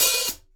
Boom-Bap Hat OP 100.wav